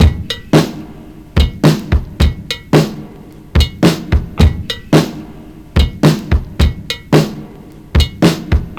• 109 Bpm Drum Loop D# Key.wav
Free drum beat - kick tuned to the D# note. Loudest frequency: 934Hz
109-bpm-drum-loop-d-sharp-key-3as.wav